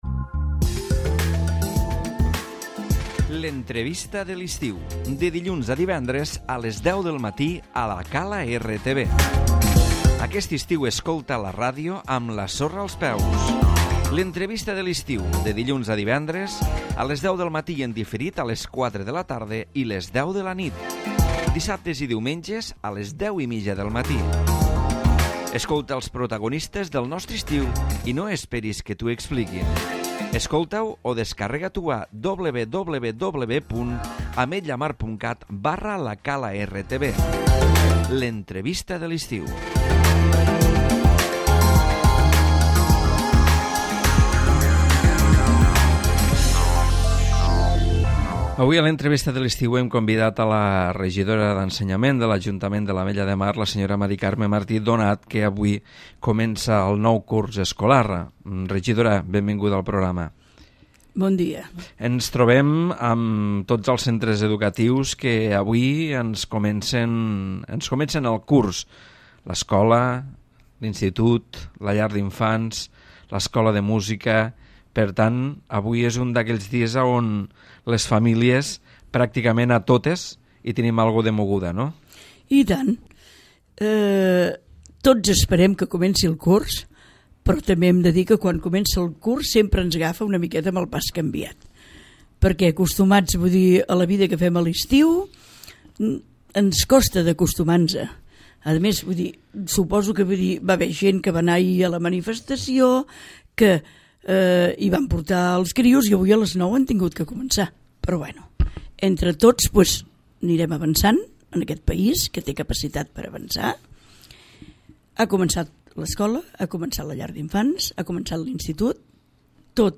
L'Entrevista
L'inici del curs escolar a la Llar d'Infants, l'Escola sant Jordi i l'Institut Mare de Deu de la Candelera, es comentat per M Carme Martí, regidora d'Ensenyament de l'Ajuntament de l'Ametlla de Mar.